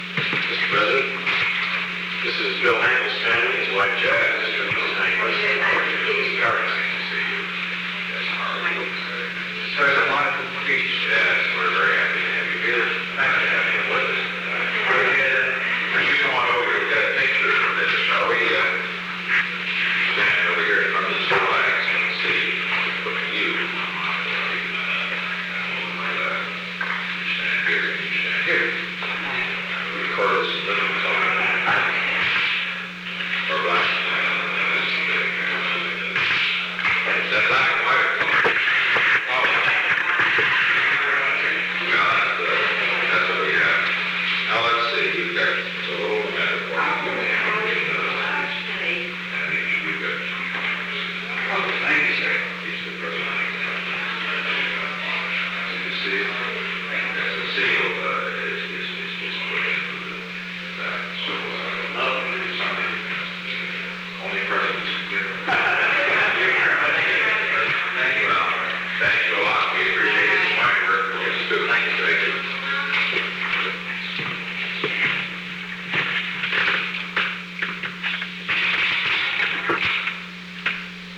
Secret White House Tapes
Location: Oval Office
General conversation